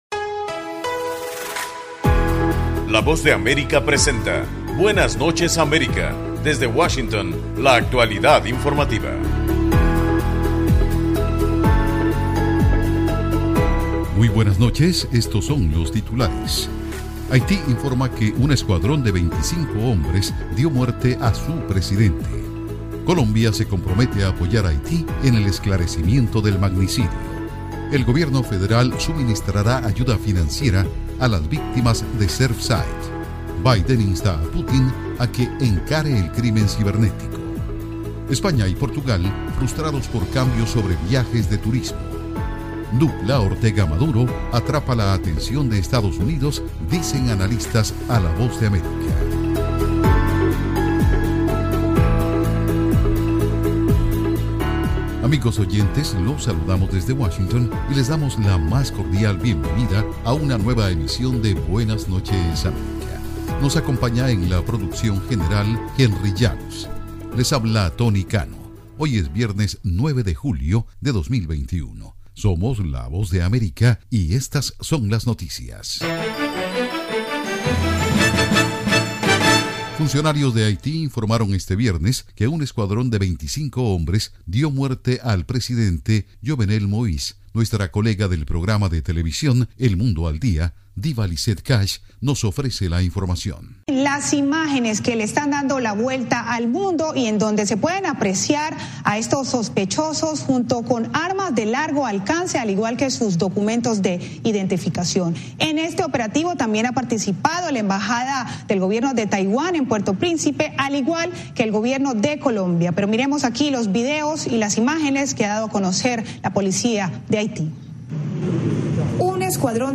PROGRAMA INFORMATIVO DE LA VOZ DE AMERICA, BUENAS NOCHES AMERICA.